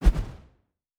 Quick Transition (4).wav